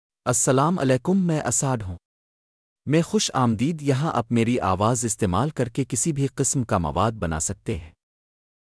MaleUrdu (Pakistan)
Asad — Male Urdu AI voice
Voice sample
Male
Asad delivers clear pronunciation with authentic Pakistan Urdu intonation, making your content sound professionally produced.